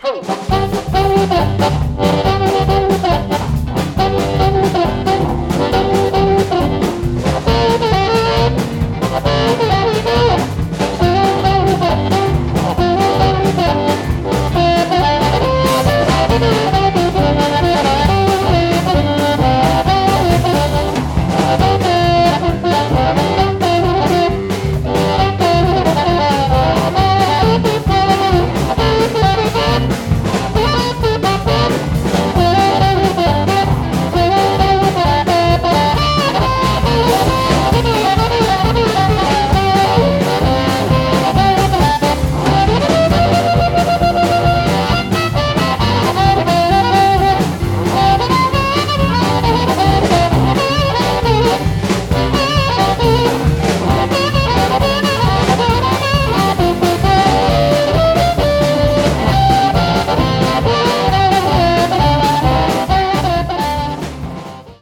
It's a fast paced show